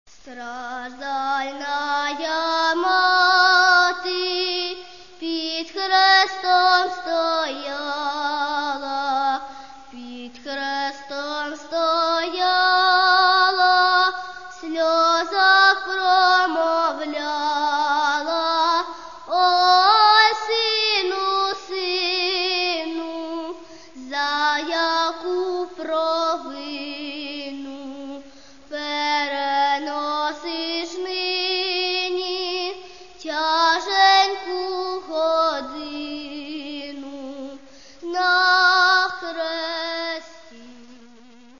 Каталог -> Народная -> Акапельное пение и хоры